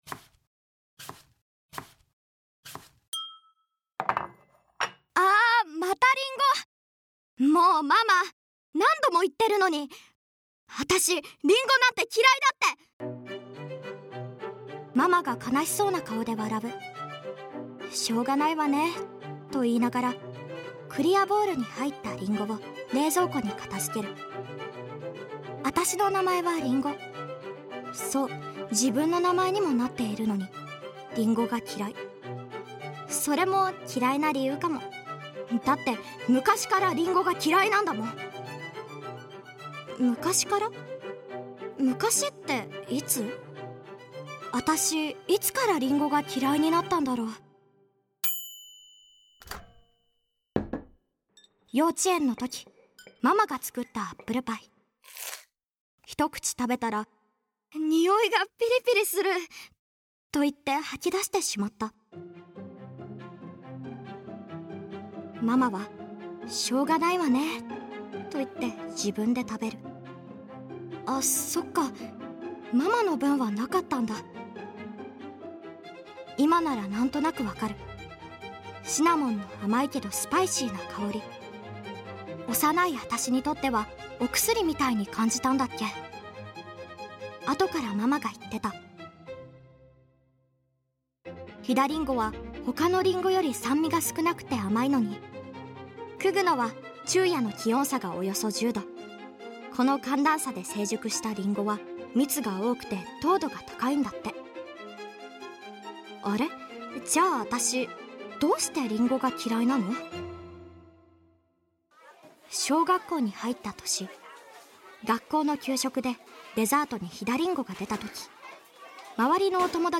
可愛らしいりんごちゃんが登場するボイスドラマ「星のりんご」を聴きながらじっくり見てくださいね！